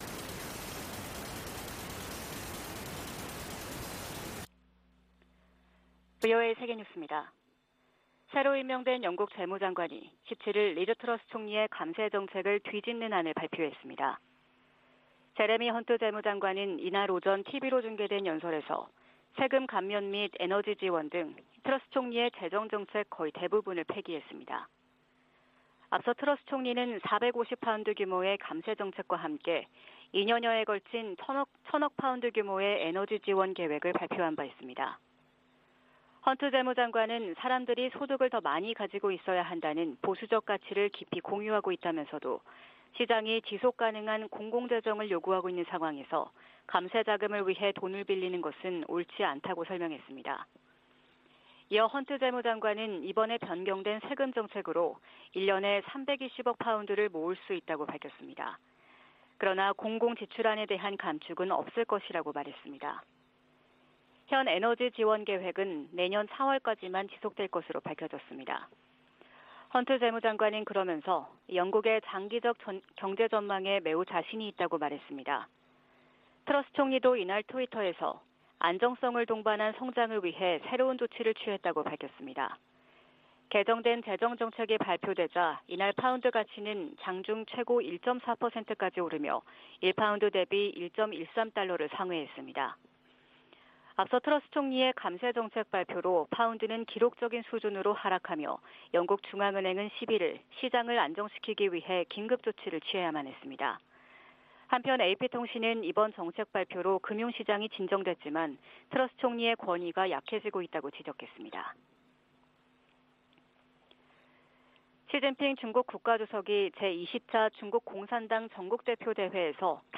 VOA 한국어 '출발 뉴스 쇼', 2022년 10월 18일 방송입니다. 북한이 연이어 9.19 남북 군사합의를 노골적으로 위반하는 포 사격에 나서면서 의도적으로 긴장을 고조시키고 있습니다. 미 국무부는 북한에 모든 도발을 중단할 것을 촉구하면서 비핵화를 위한 외교와 대화에 여전히 열려 있다는 입장을 재확인했습니다. 유엔은 북한의 안보리 결의 위반을 지적하며 대화 재개를 촉구했습니다.